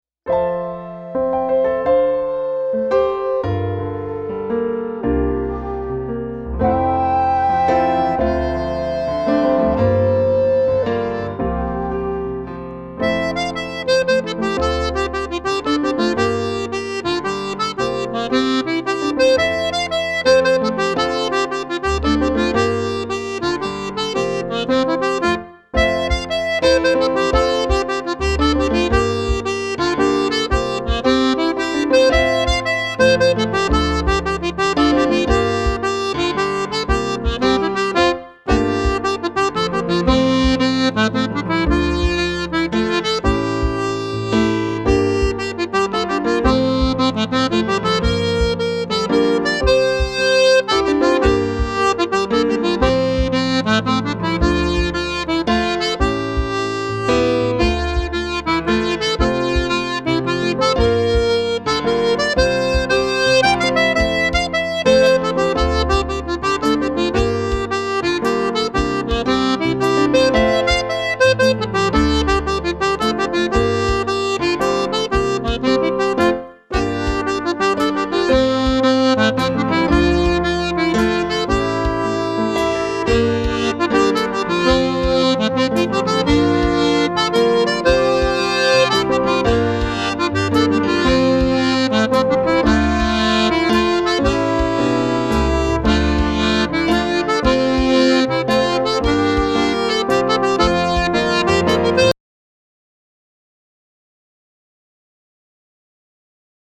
Slip Jigs